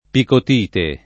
picotite [ pikot & te ]